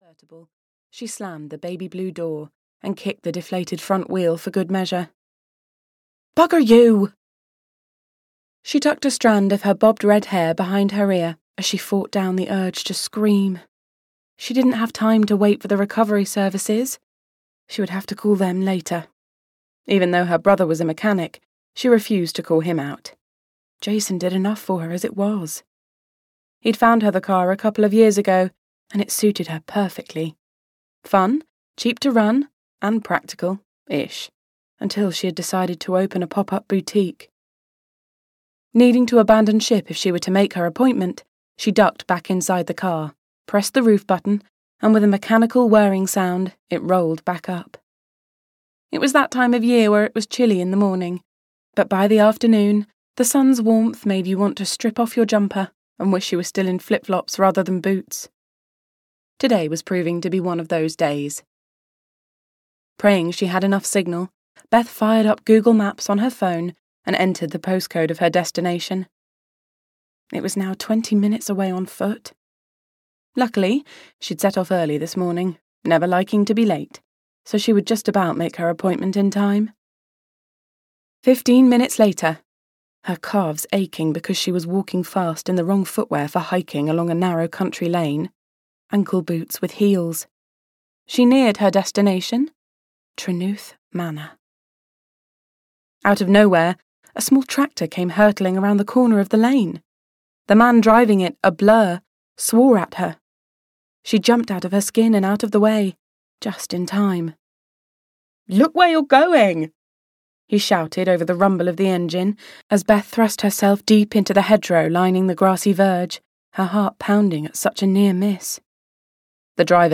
Mistletoe at the Manor (EN) audiokniha
Ukázka z knihy